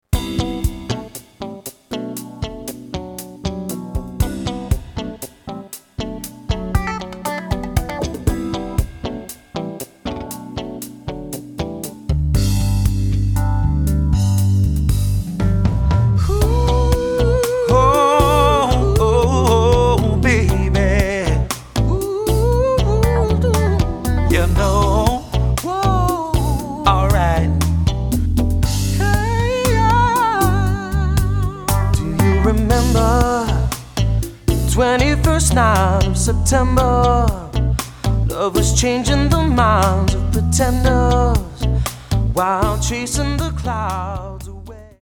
Genre: Island contemporary, world music.